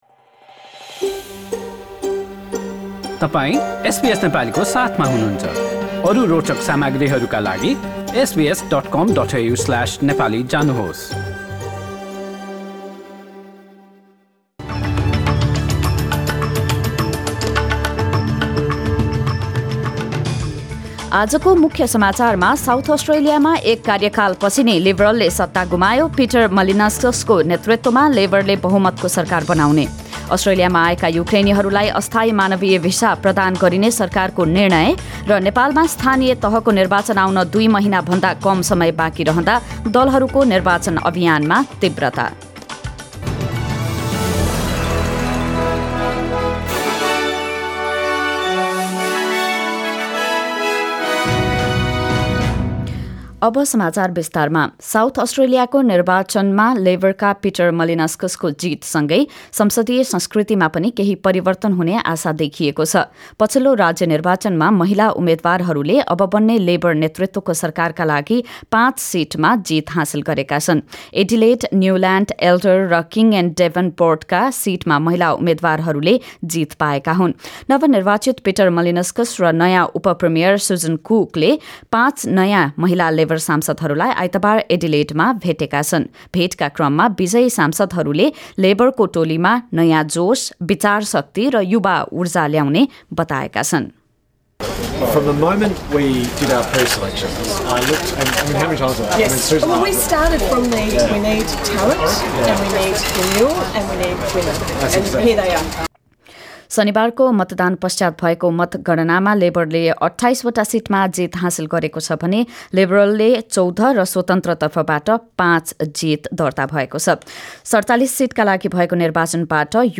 एसबीएस नेपाली अस्ट्रेलिया समाचार: आइतबार २० मार्च २०२२